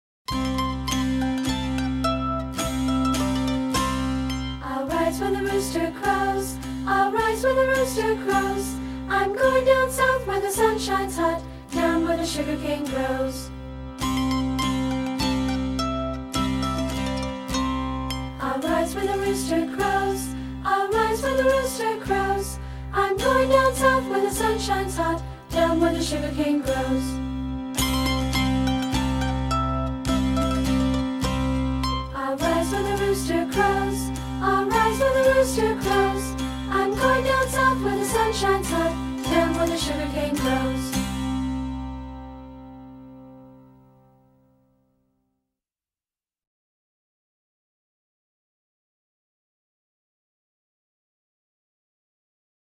This is an old folk song from Appalachia.  It’s about a person from the South who is in the North and feels homesick and is deciding to go back down South.